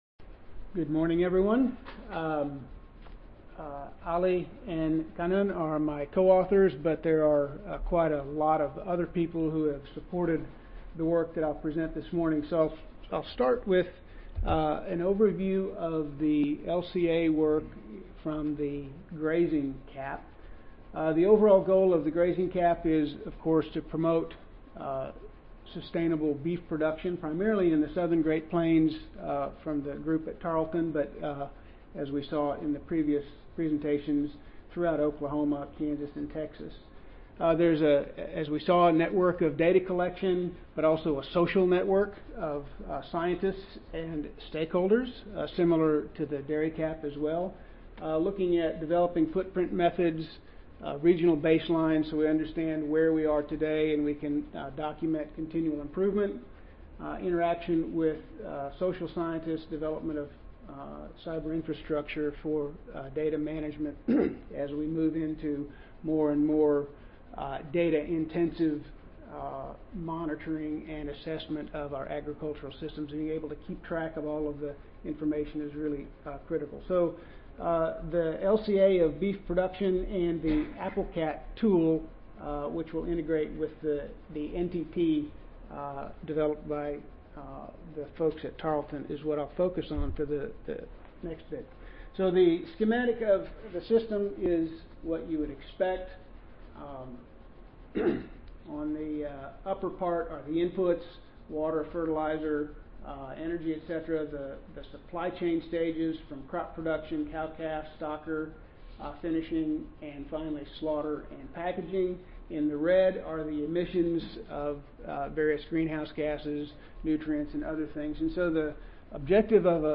Tarleton State University Audio File Recorded Presentation